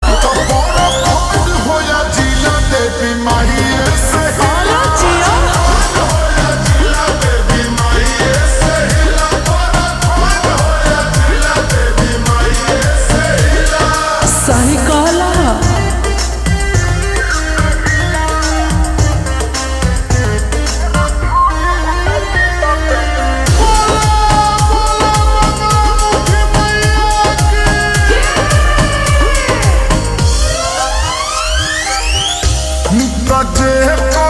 Bhojpuri Songs
( Slowed + Reverb)